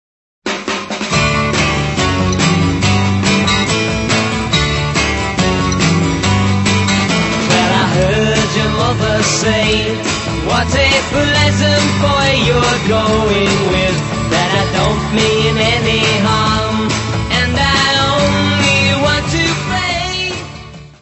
Área:  Pop / Rock
Mono